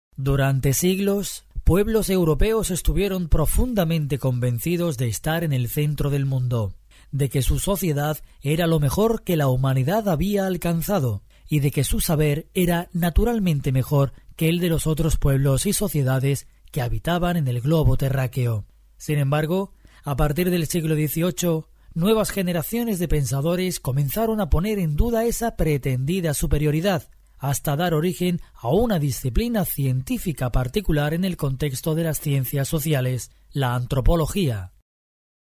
Locutor Profesional con más de 20 años de experiencia.
Sprechprobe: Sonstiges (Muttersprache):